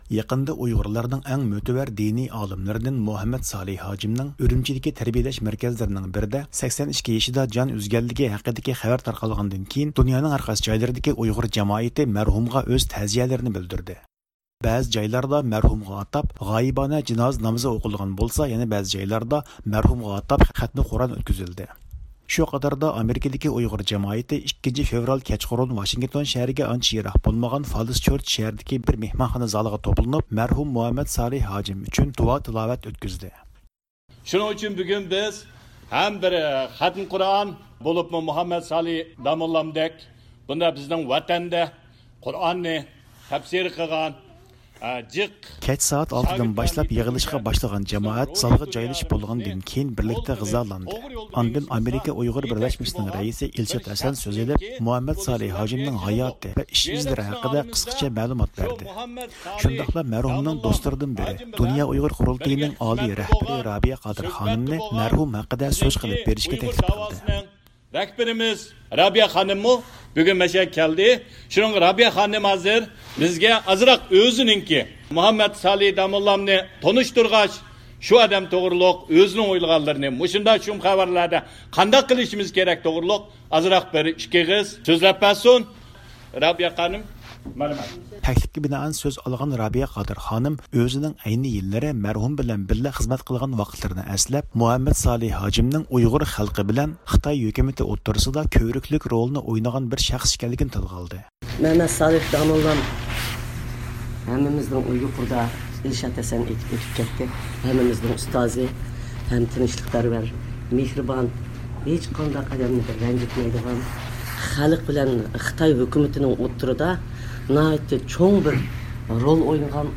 پائالىيەت ئاخىرىدا بۇ قېتىمقى يىغىلىشقا ئىشتىراك قىلغان دۇنيا ئۇيغۇر قۇرۇلتىيىنىڭ رەئىسى دولقۇن ئەيسا زىيارىتىمىزنى قوبۇل قىلدى.